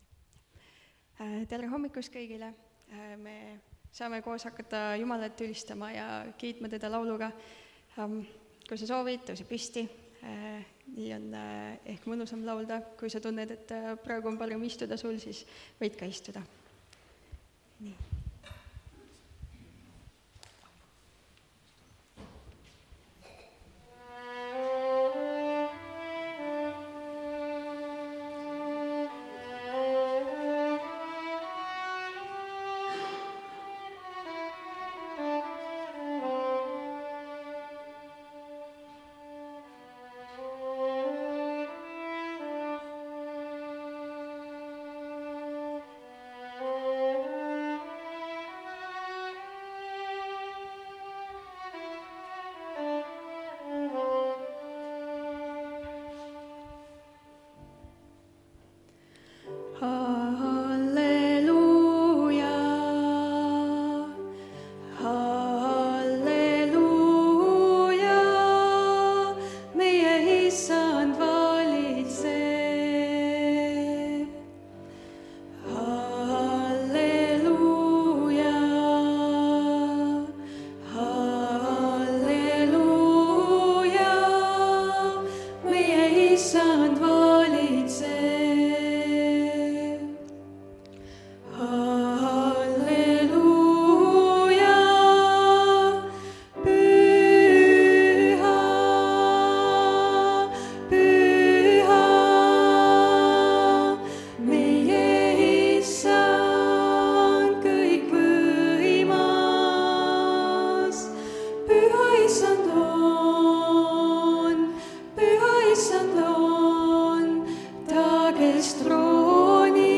Tunnistuste koosolek (Tallinnas)
Jutlused